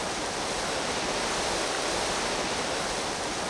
1 channel
onebzzt.wav